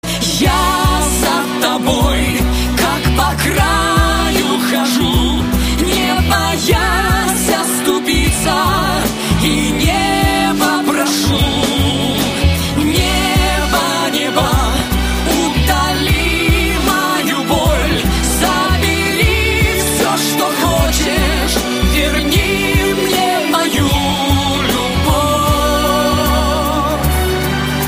• Качество: 128, Stereo
мужской вокал
женский вокал
лирика
спокойные
дуэт